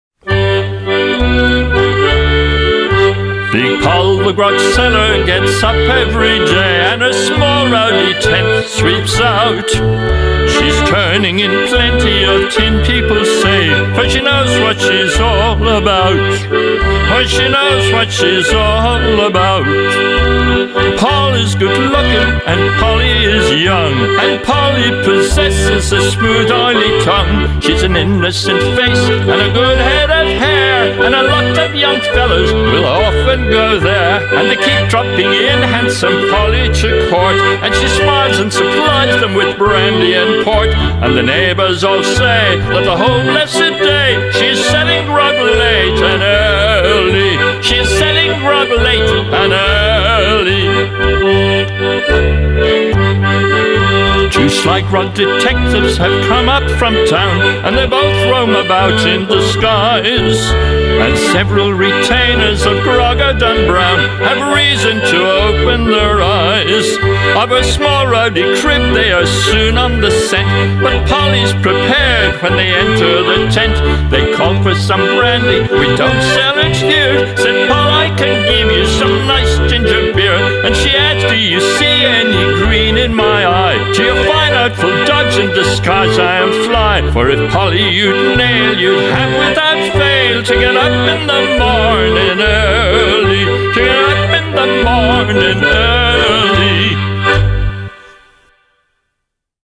our Honorary Musicologist has recorded this song for the blog.